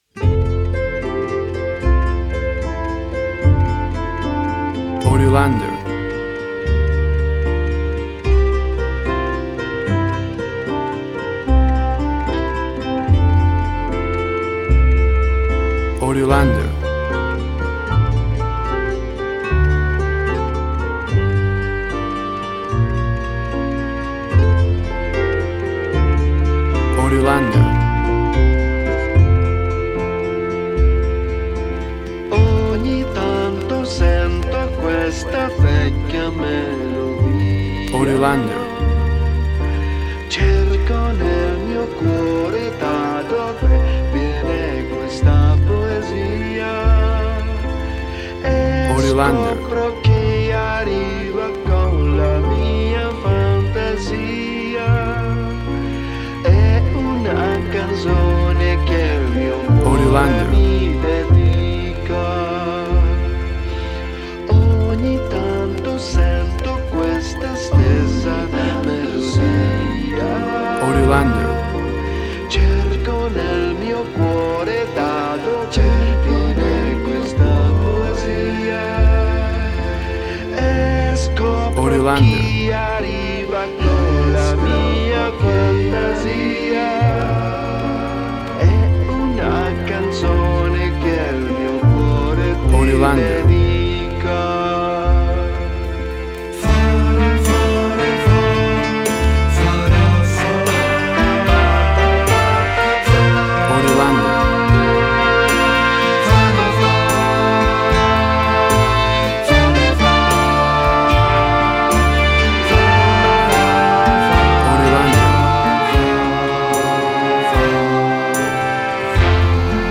WAV Sample Rate: 24-Bit stereo, 48.0 kHz
Tempo (BPM): 74